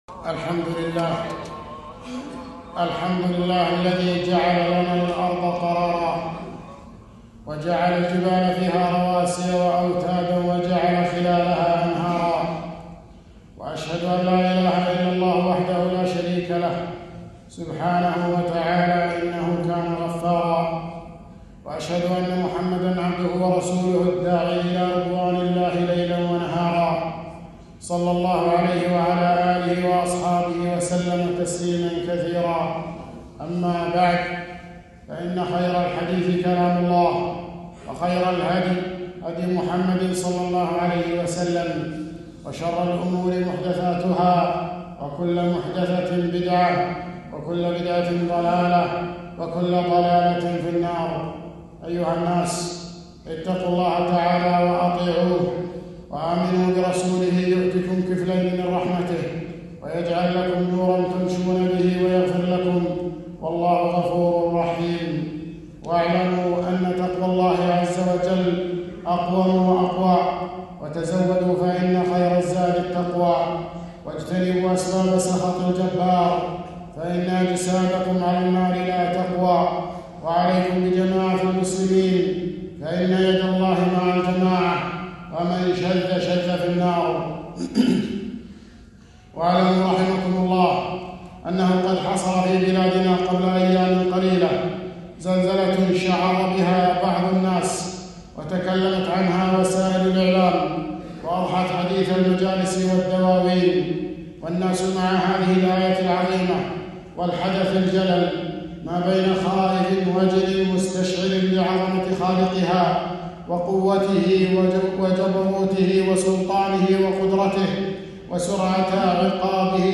خطبة - إذا زلزلت الأرض